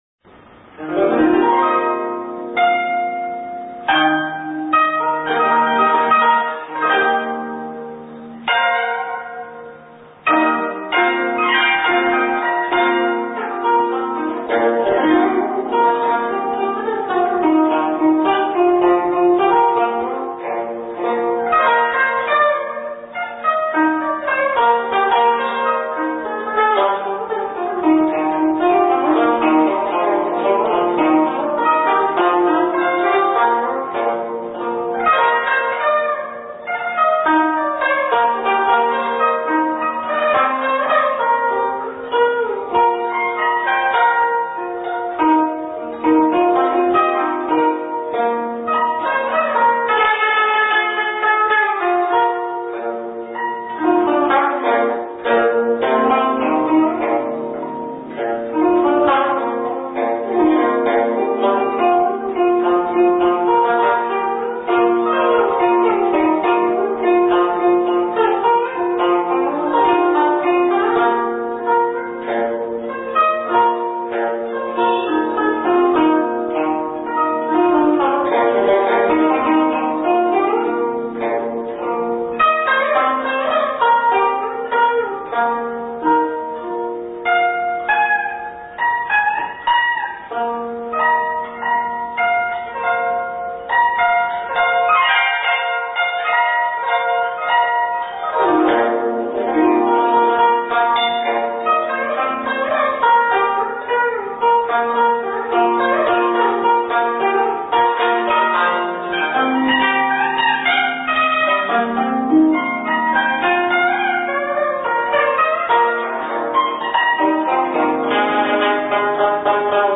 古箏
樂曲材料源於“八板”的變體。運用頓音和加花等技巧，用節奏的頓挫，連斷對比和短碎處理，使之形象生動，優美動人。